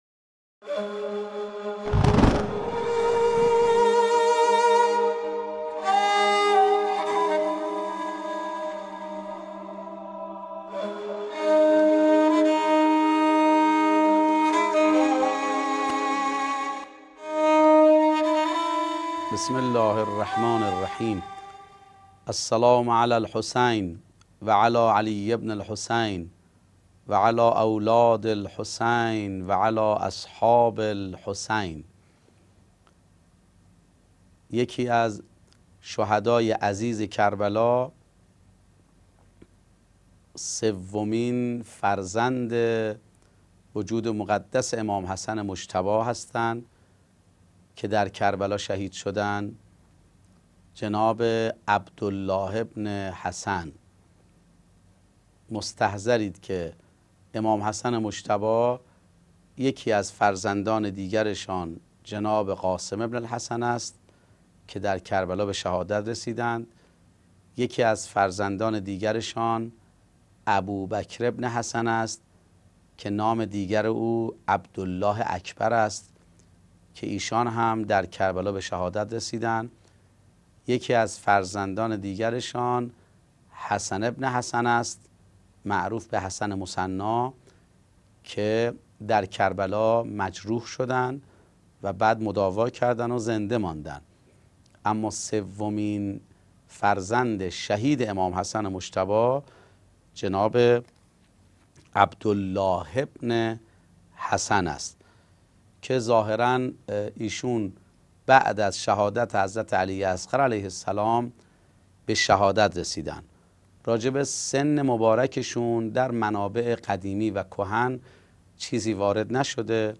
سلسله گفتارهایی